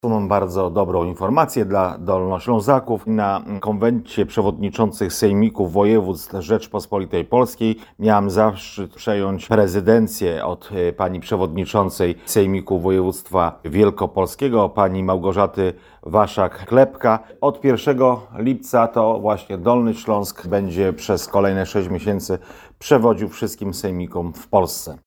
Dolny Śląsk przejął prezydencję po Sejmiku Województwa Wielkopolskiego, mówi Andrzej Kredkowski – Wiceprzewodniczący Sejmiku Województwa Dolnośląskiego.